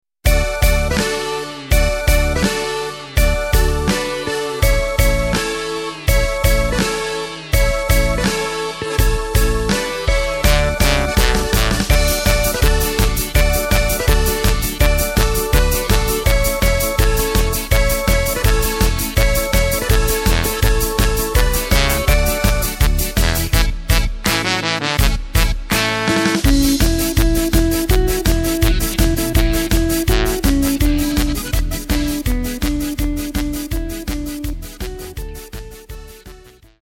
Takt:          2/4
Tempo:         165.00
Tonart:            D
VolxxMusik aus dem Jahr 2012!